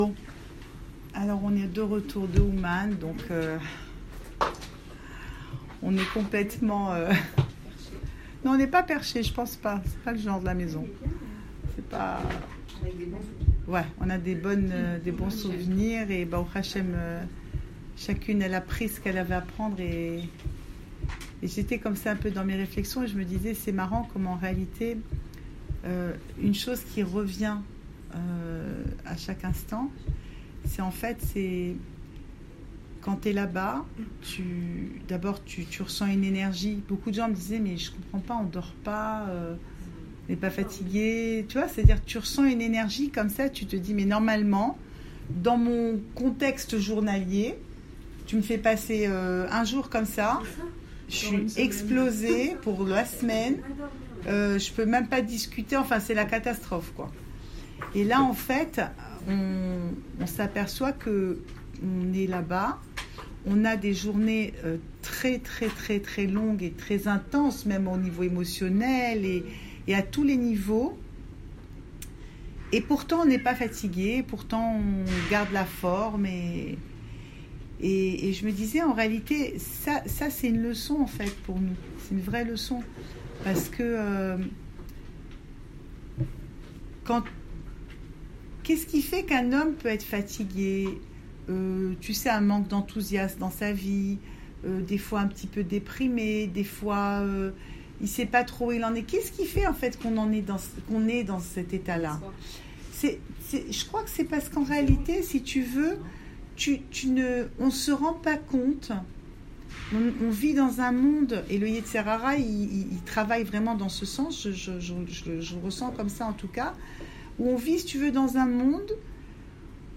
Cours audio Le coin des femmes Pensée Breslev Voyage à Ouman - 26 juin 2018 28 juin 2018 Retour d’Ouman… Enregistré à Raanana